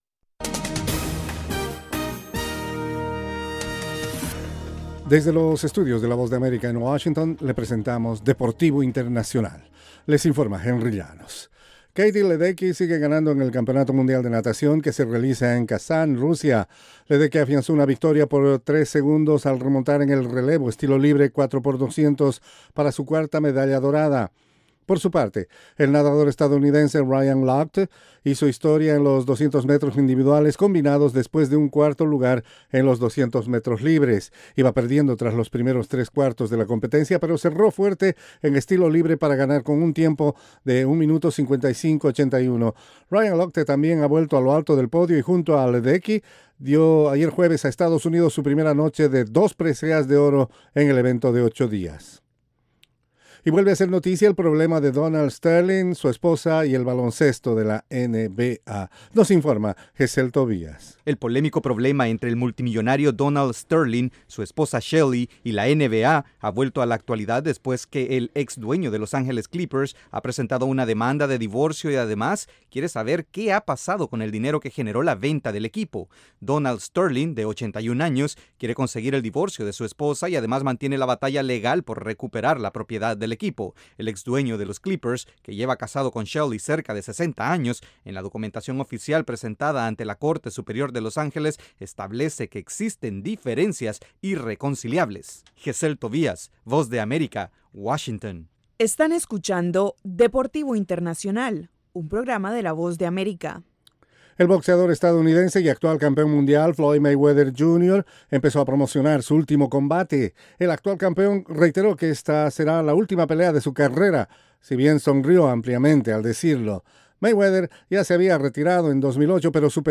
La información deportiva en cinco minutos, desde los estudios de la Voz de América.